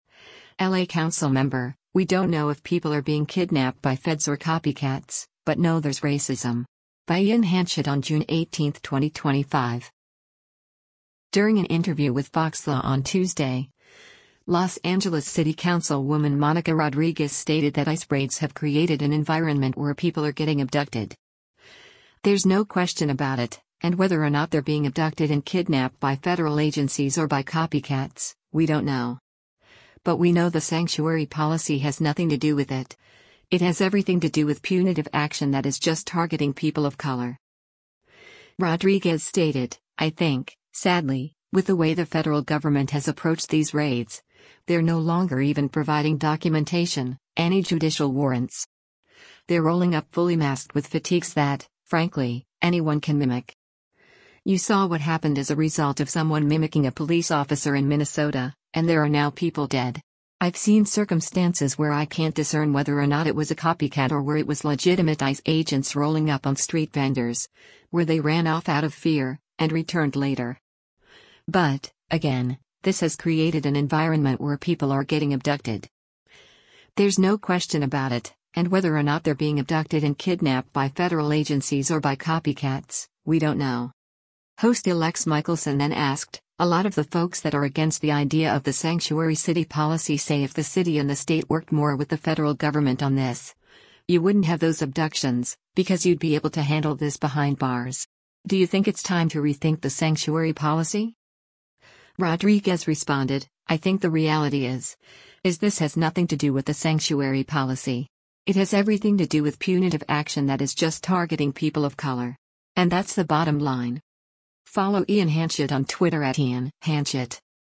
During an interview with FOXLA on Tuesday, Los Angeles City Councilwoman Monica Rodriguez stated that ICE raids have “created an environment where people are getting abducted.